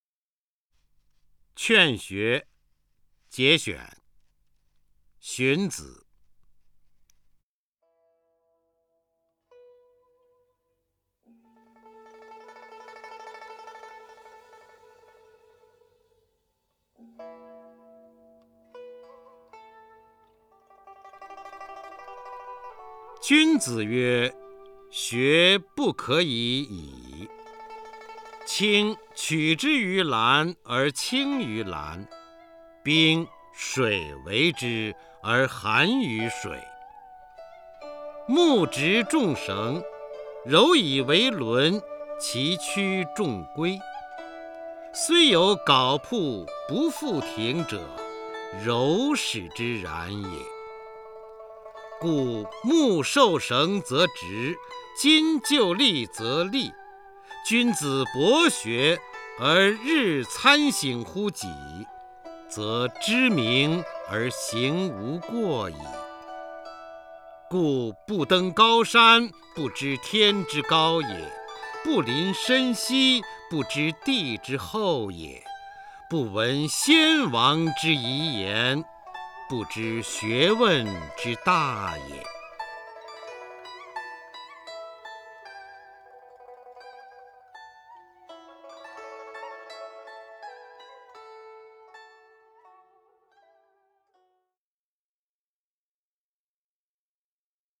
首页 视听 名家朗诵欣赏 方明
方明朗诵：《劝学（节选）》(（战国）荀子)